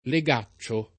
leg#©©o] s. m.; pl. ‑ci — tosc. legacciolo [leg#©©olo]: i legàccioli di sovatto che serravano il volume dal taglio rossastro [i leg#©©oli di Sov#tto ke SSerr#vano il vol2me dal t#l’l’o roSS#Stro] (D’Annunzio)